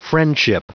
Prononciation du mot friendship en anglais (fichier audio)